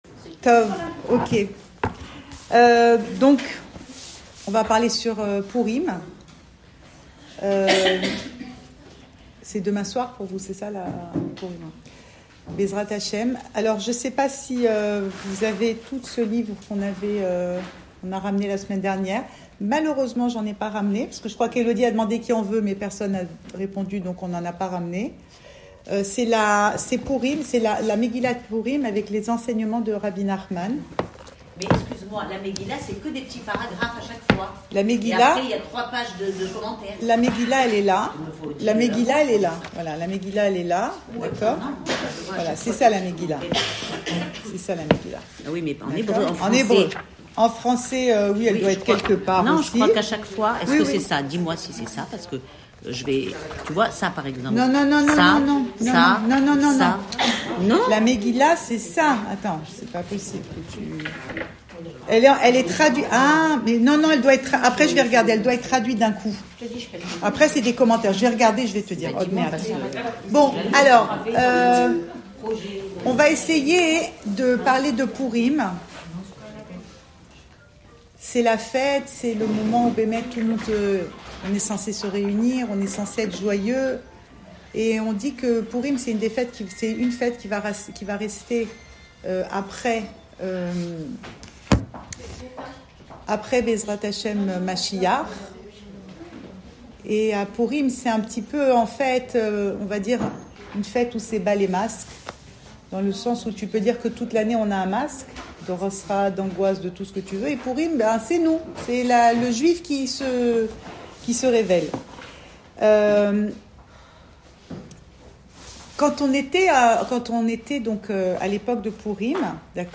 Cours audio Fêtes Le coin des femmes Pensée Breslev - 25 février 2021 25 février 2021 Pourim : enlève ton déguisement. Enregistré à Tel Aviv